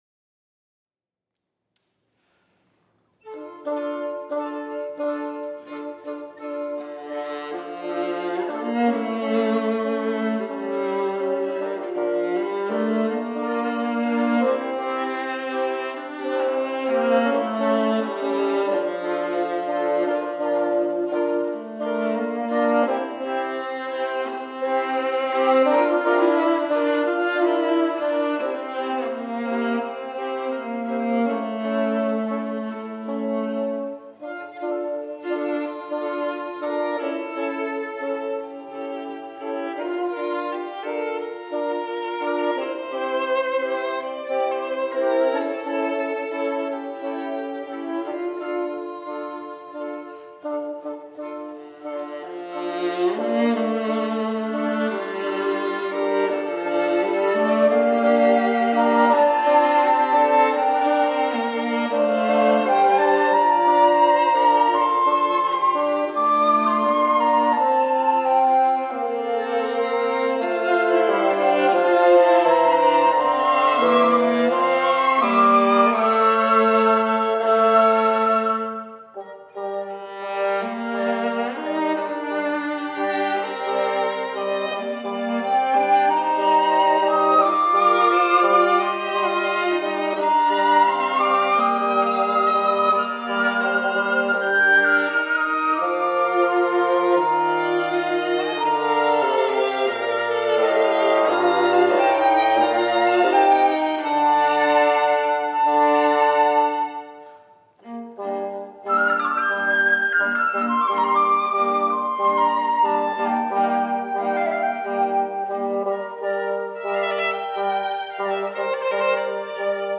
I've just completed a new work for flute, bassoon, violin, and viola titled Fantasy.